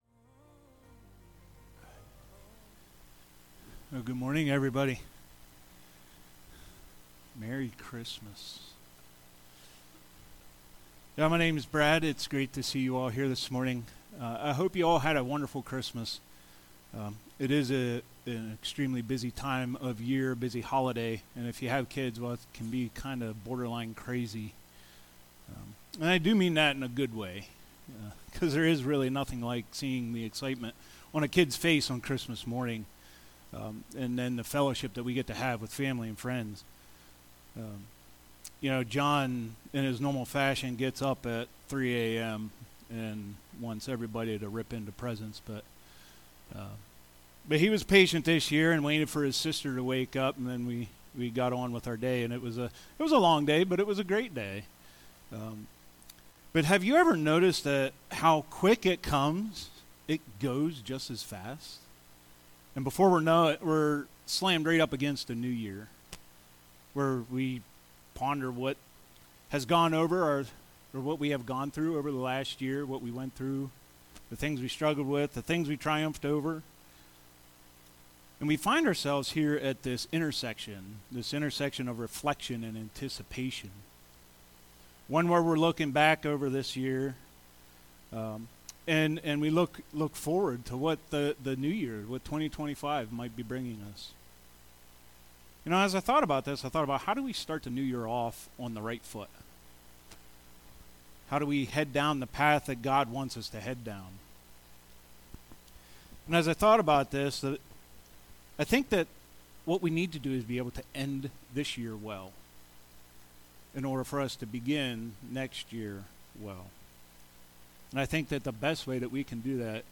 Sermons | Watermarke Church